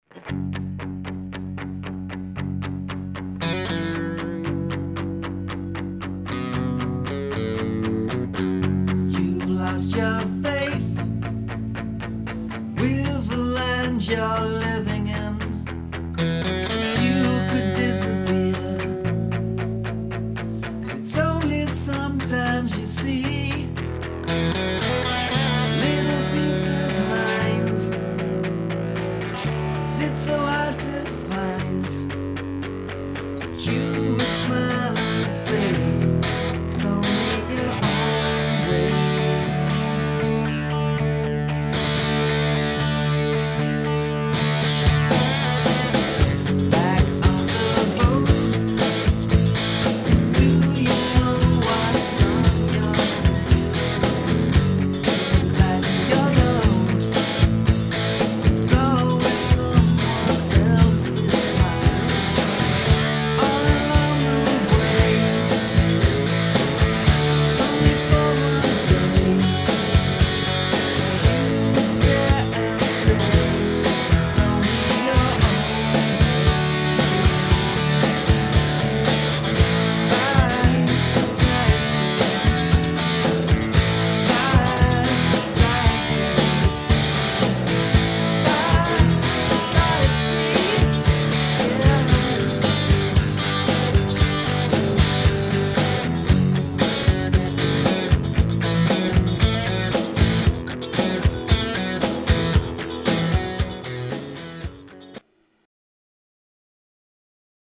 Another unfinished track, made up of only two verses and a bit of a chorus which I never liked, but the verse I liked a lot.
I think it was mainly the grungyness of the guitars that I liked and the drums coming in and lifting them.